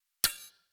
SFX_UI_Click.wav